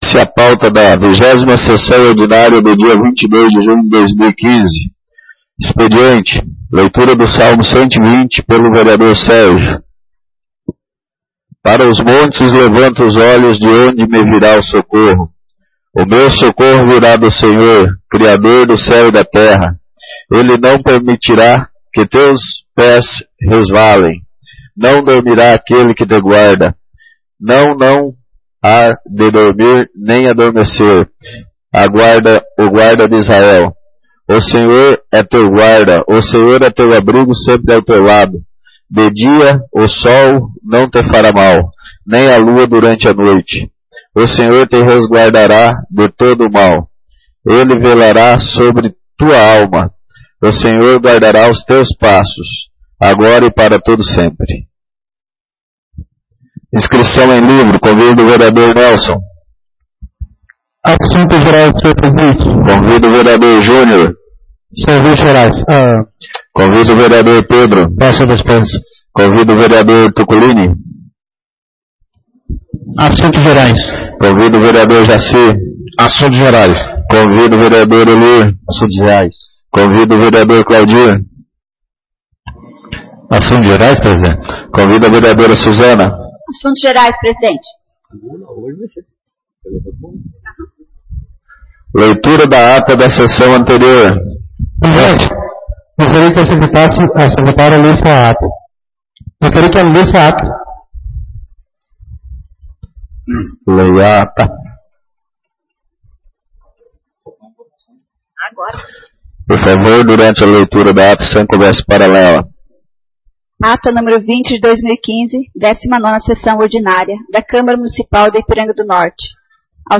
Vigésima Sessão Ordinária — Câmara Municipal de Ipiranga do Norte
Vigésima Sessão Ordinária